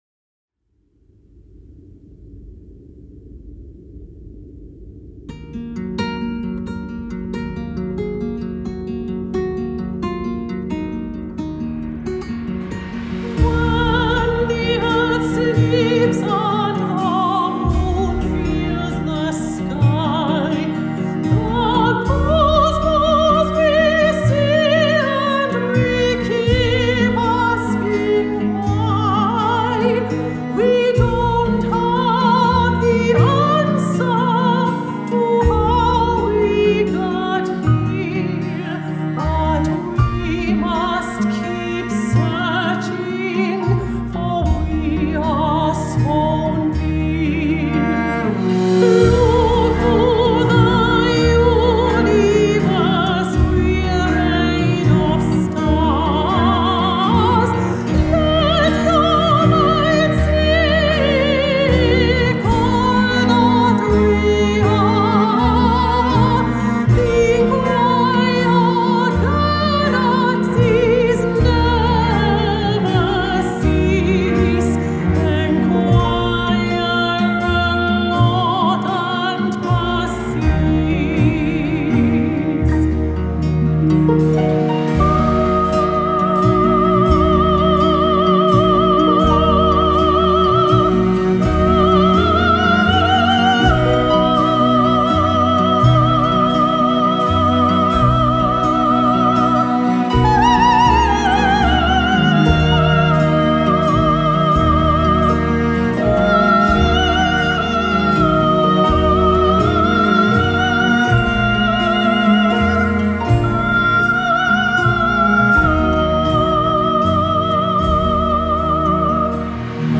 With over twenty minutes of classical/folk/ crossover tracks
crystal clear spinto soprano voice
modern acoustic sounds
Airtight Studios in Manchester